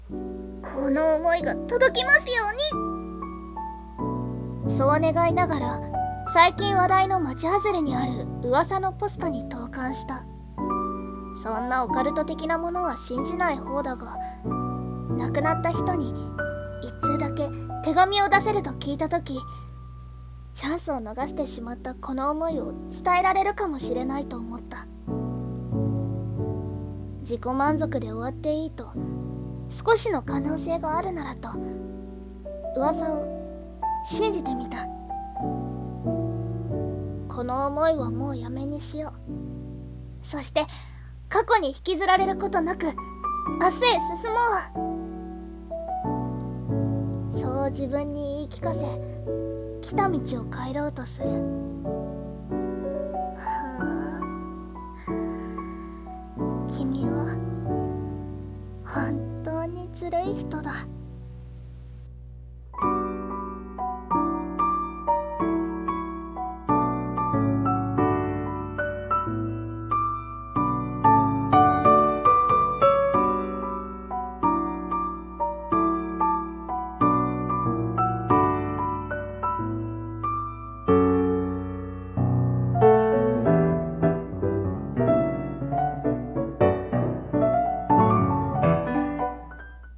1人声劇】魔法のポスト